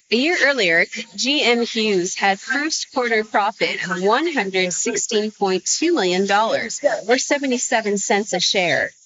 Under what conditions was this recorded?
audio-denoising audio-to-audio Denoiser from Resemble